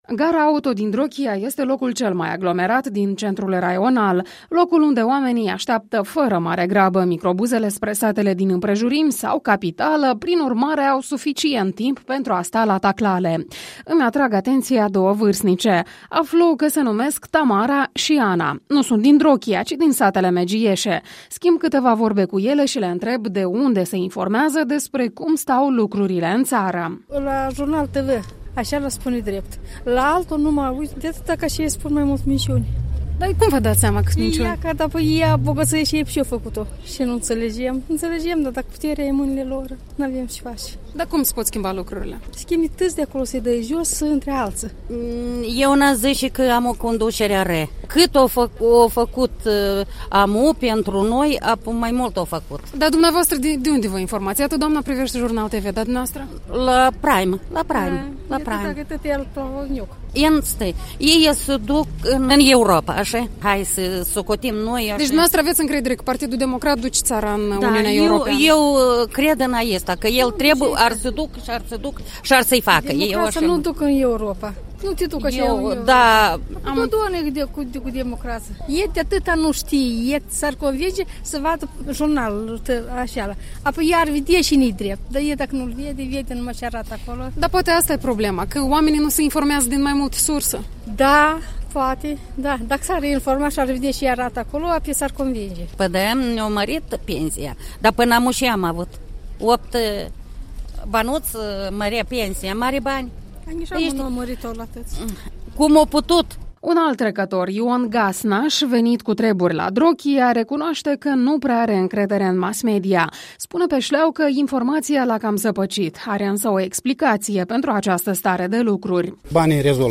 La Drochia.